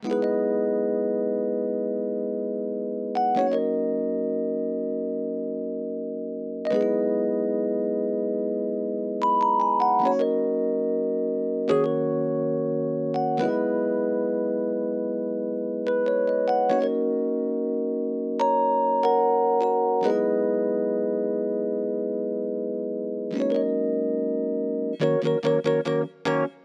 05 rhodes B.wav